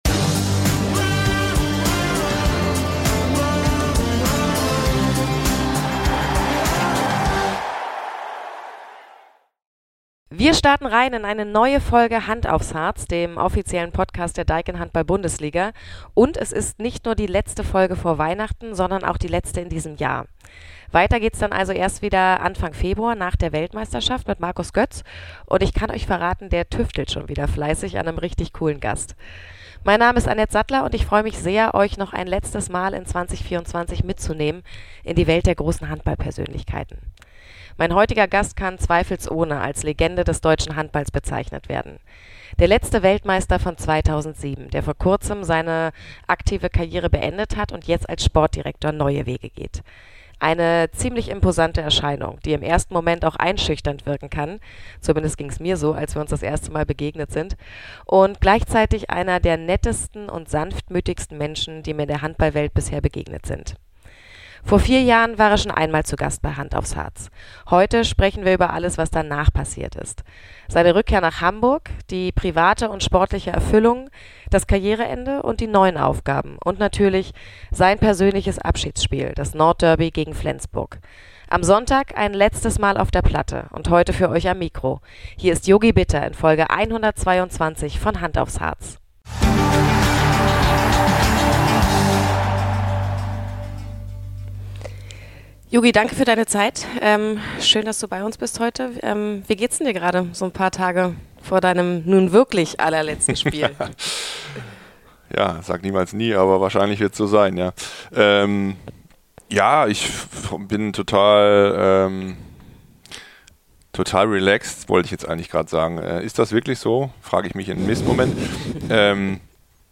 Spannende Insider-Informationen und tiefgründige Interviews mit den besten Gästen aus dem Handballsport